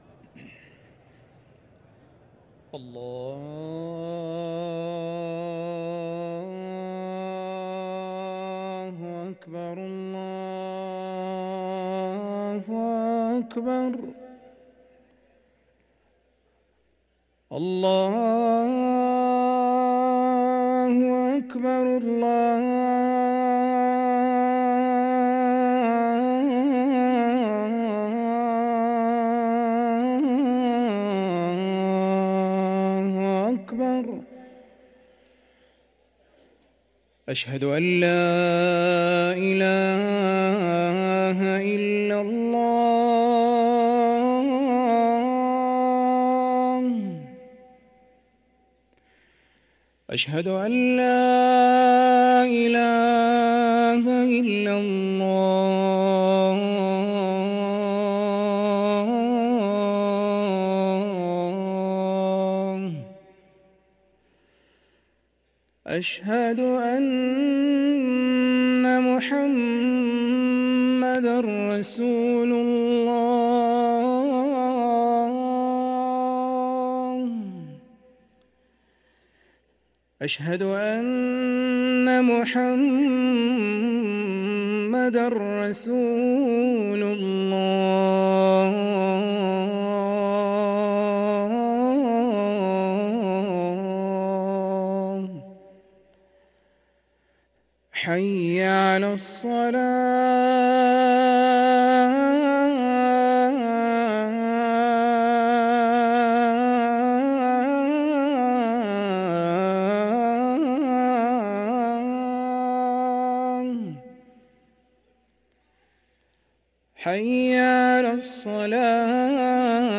ركن الأذان 🕋